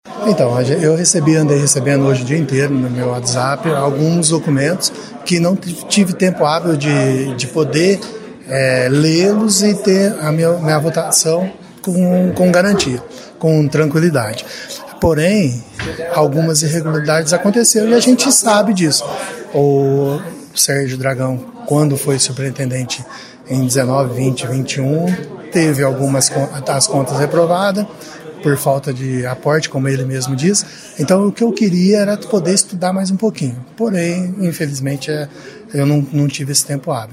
A aprovação de Sérgio contou com apenas um voto contrário, do vereador Rui Nova Onda (União), que justificou sua posição alegando que precisava de mais tempo para analisar documentos relacionados às denúncias de corrupção.